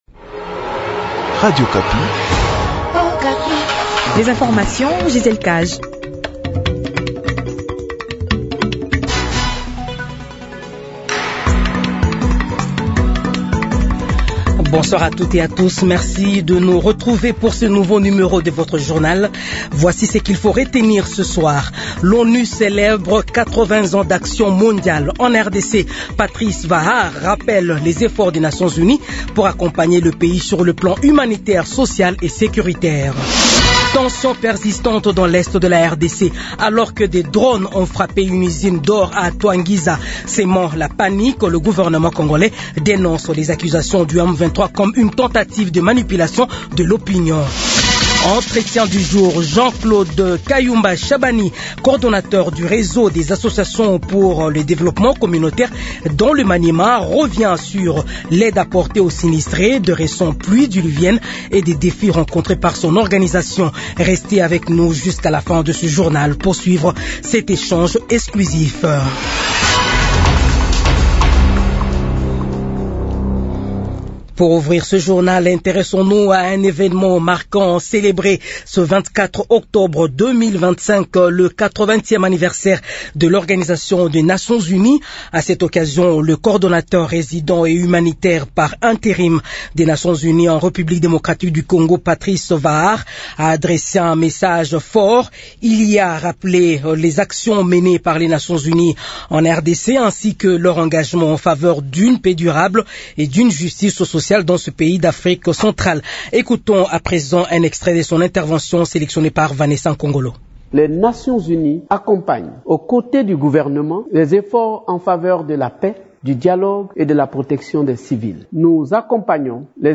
Journal 18h